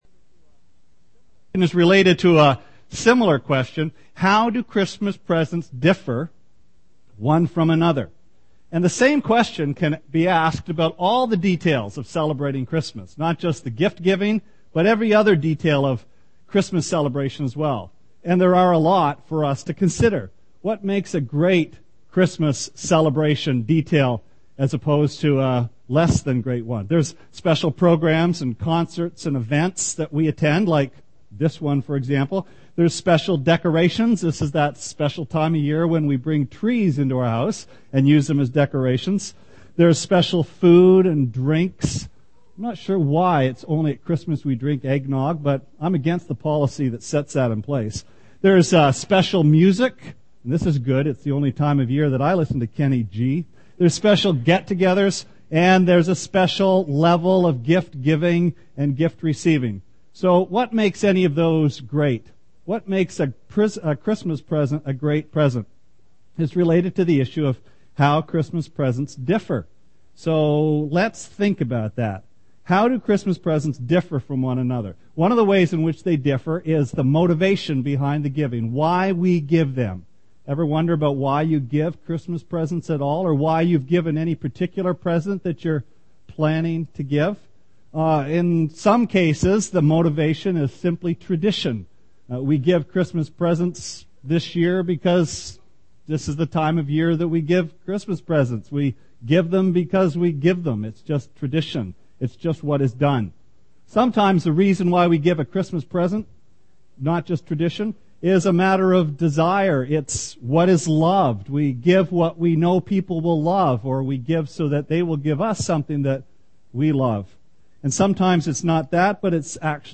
Sermon Archives Dec 24
Christmas eve service.